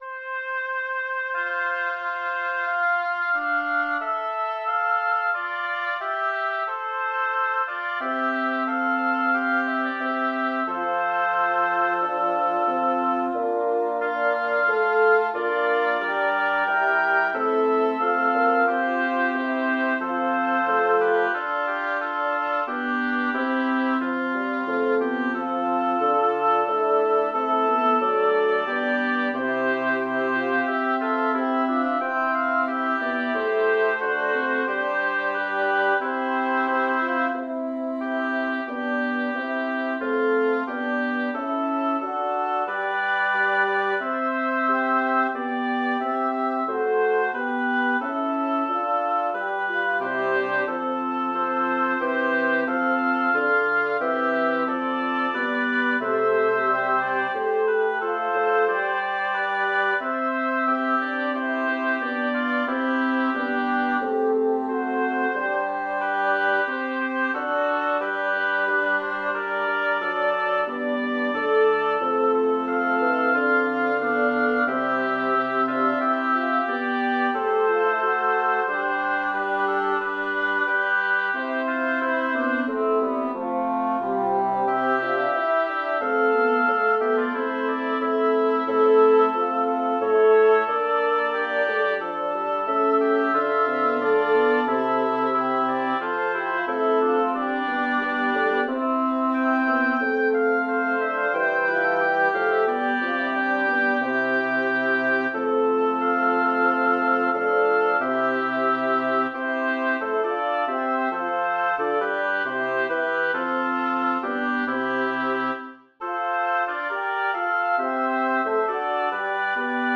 Title: Expurgate vetus fementum Composer: Rinaldo del Mel Lyricist: Number of voices: 5vv Voicing: SATTB Genre: Sacred, Motet
Language: Latin Instruments: A cappella